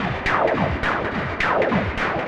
Index of /musicradar/rhythmic-inspiration-samples/105bpm
RI_RhythNoise_105-04.wav